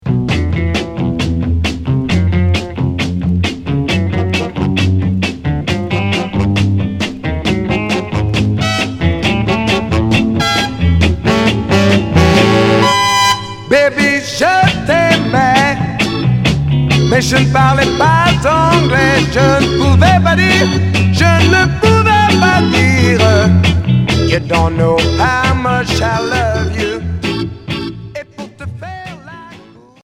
Chanteur 60's